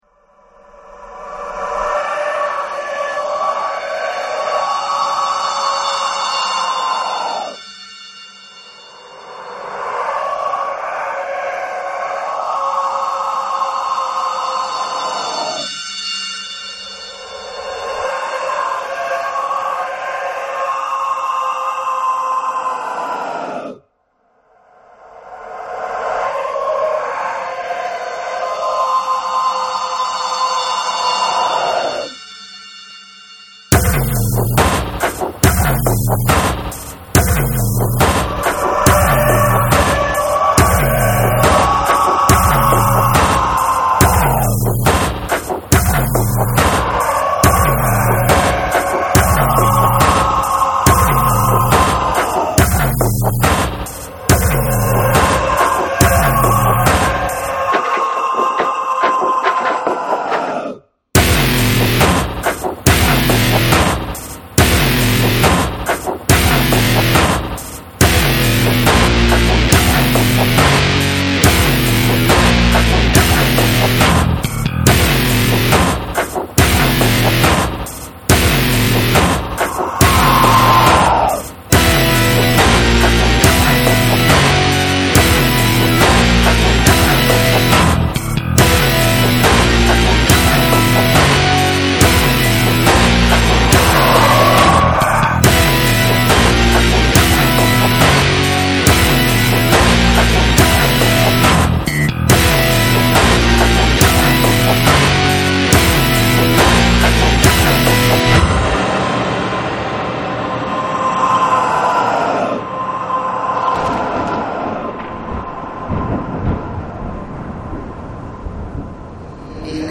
di genere industrial-dark